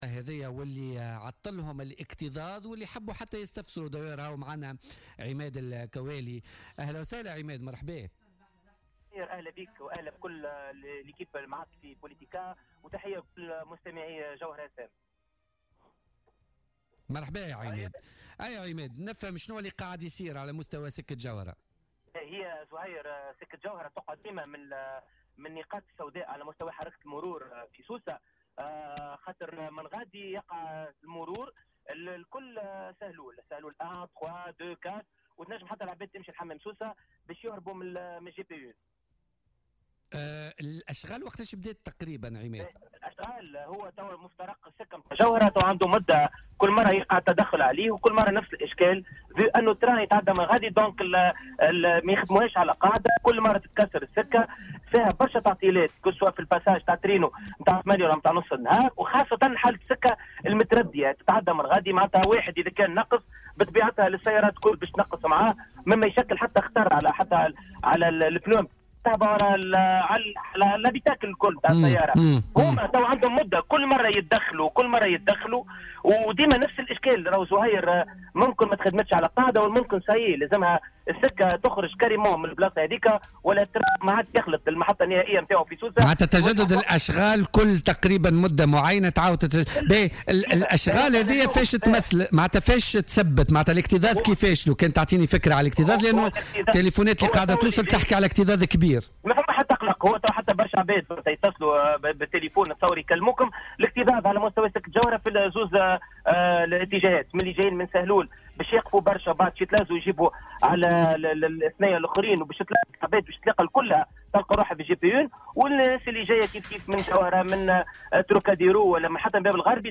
تتسبب الأشغال على مستوى سكة جوهرة بسوسة في اكتظاظ مروري يومي خانق وفق ما أكده أحد مستعملي هذا الطريق في مداخلة له في بوليتيكا.